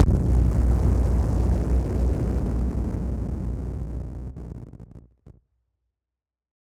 BF_SynthBomb_A-04.wav